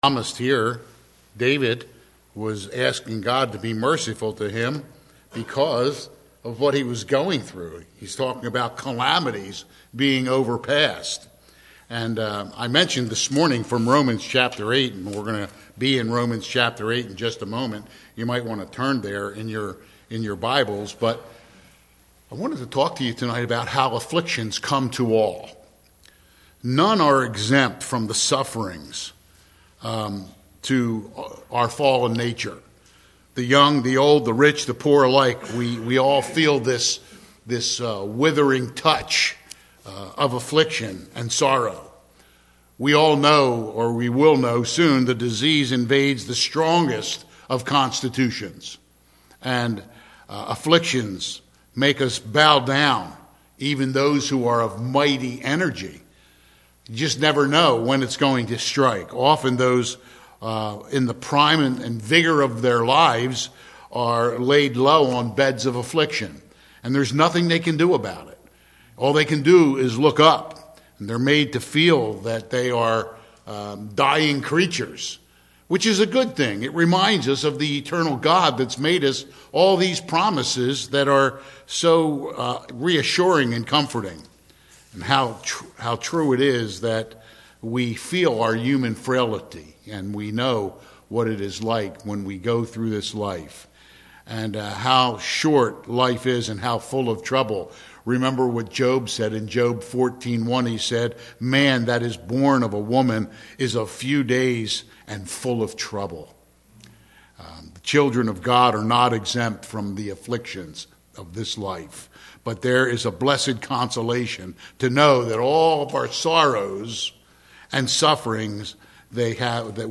Psalm 42:5 Service Type: Sunday AM « February 25